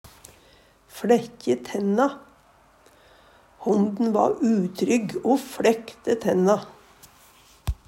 DIALEKTORD PÅ NORMERT NORSK fLækkje tenna flekkje tenner, vise tenner t.d. noko hundar gjer Eksempel på bruk Honden va utrygg o fLækte tenna.